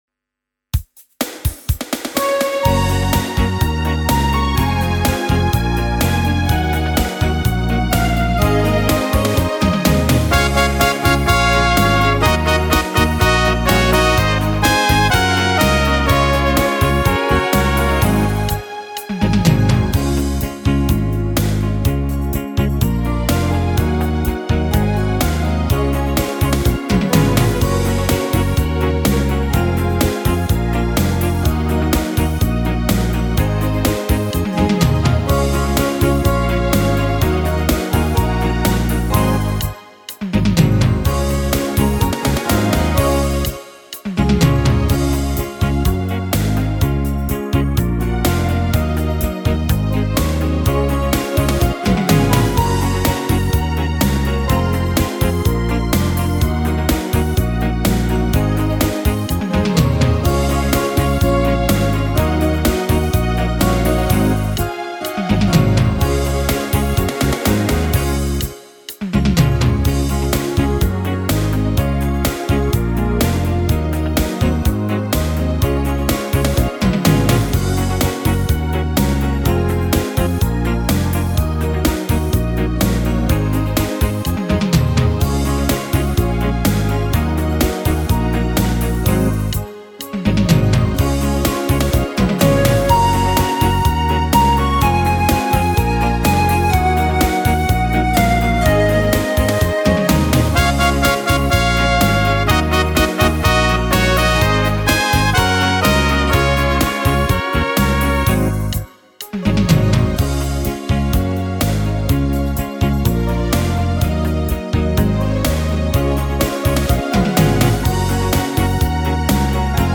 Tone Nữ (C#)
•   Beat  01.